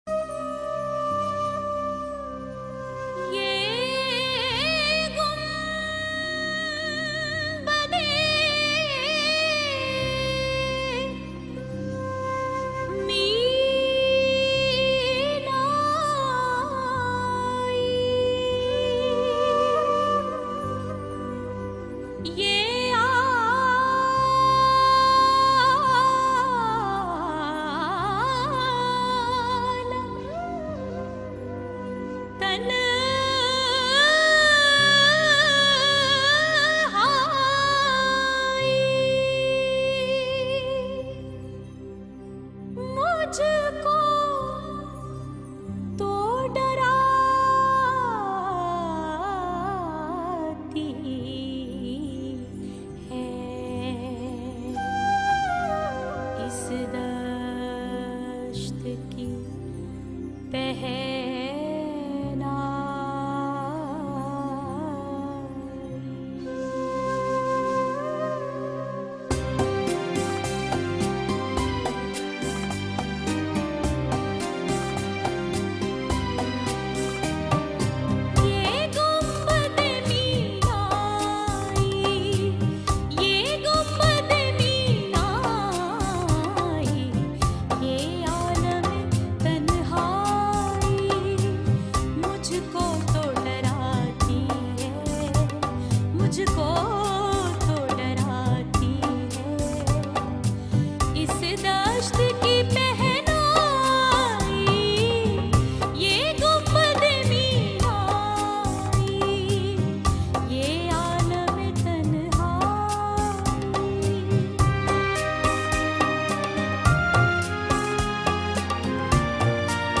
Arfana & Sufiana Kalam